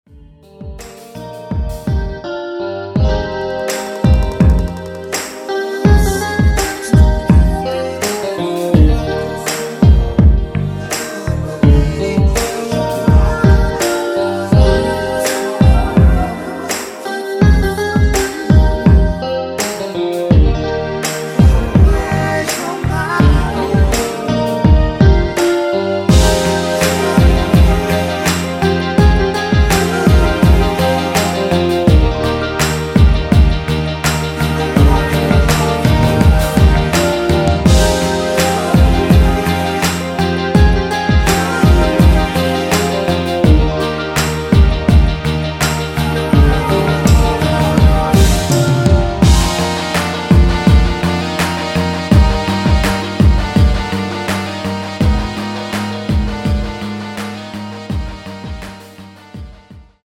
원키에서(-1)내린 코러스 포함된 MR입니다.
엔딩이 페이드 아웃이라 노래 부르기 좋게 엔딩을 만들어 놓았습니다.
Eb
앞부분30초, 뒷부분30초씩 편집해서 올려 드리고 있습니다.